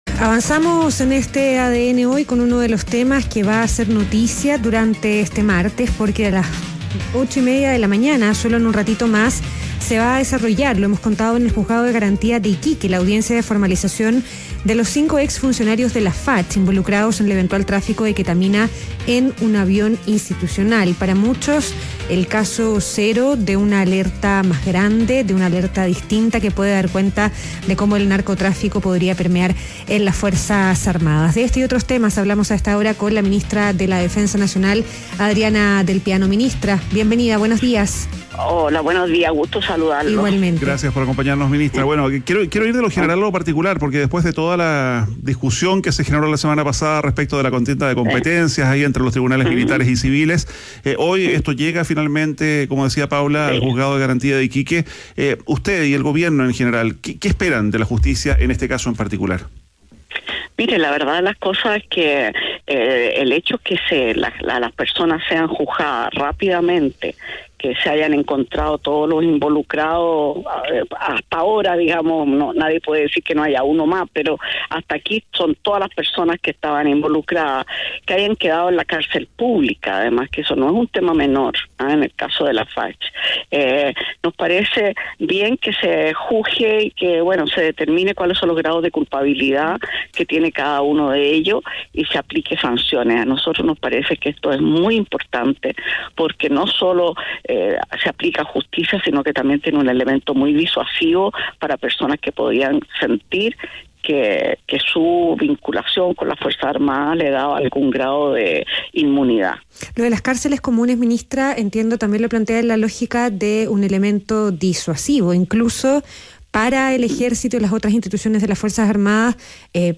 Entrevista a Adriana Delpiano, ministra de Defensa - ADN Hoy